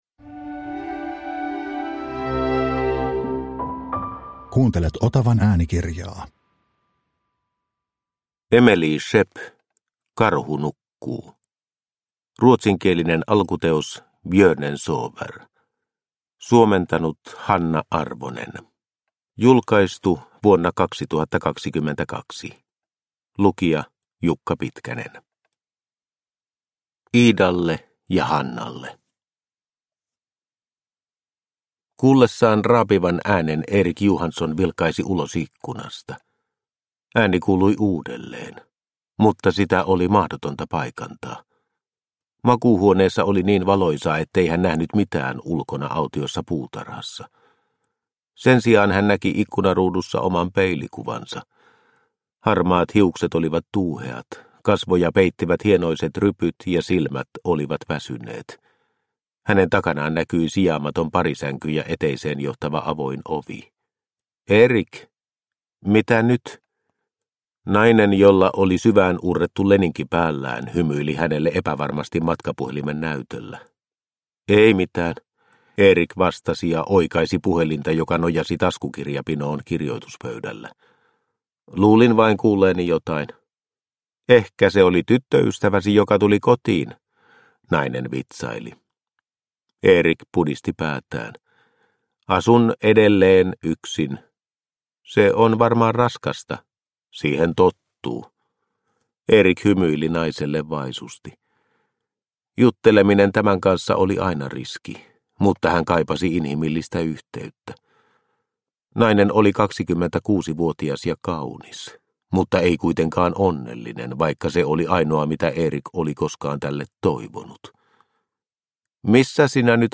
Karhu nukkuu – Ljudbok – Laddas ner